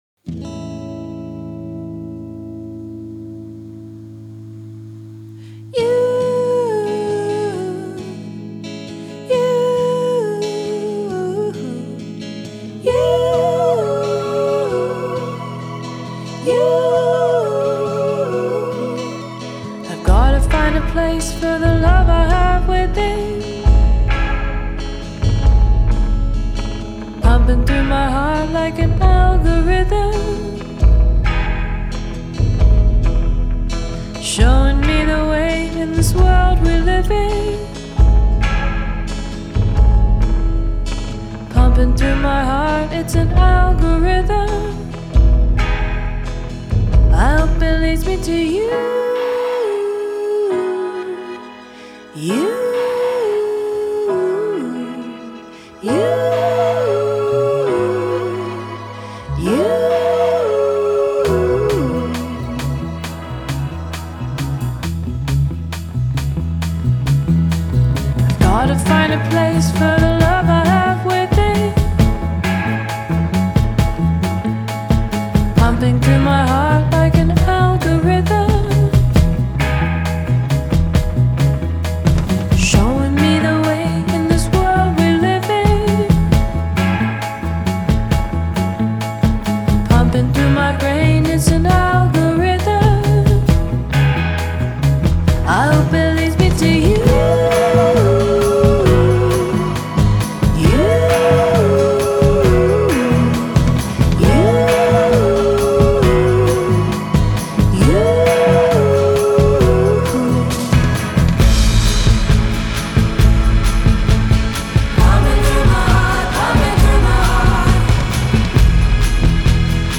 Genre: Indie Folk, Singer-Songwriter